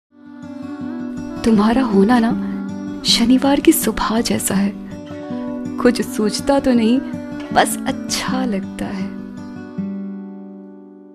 Delhi female hindi voice artist for narrations, ivr, corporate, elearning, training audio